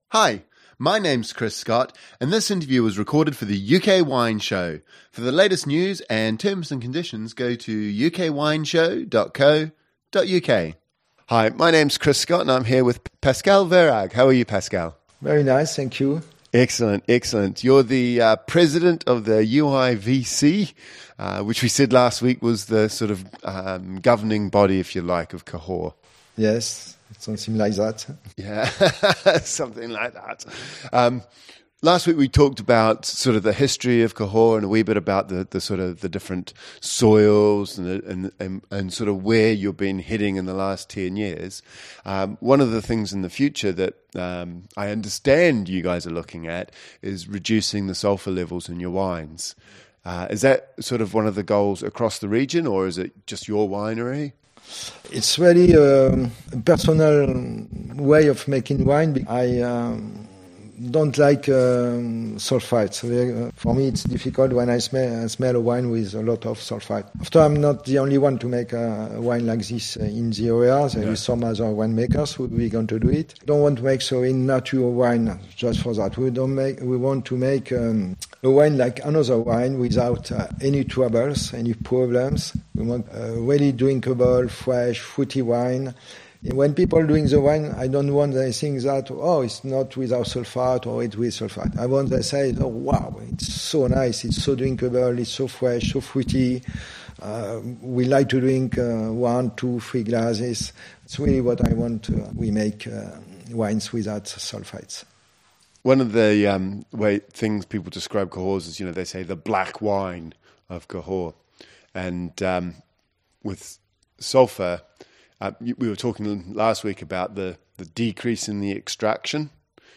In this second interview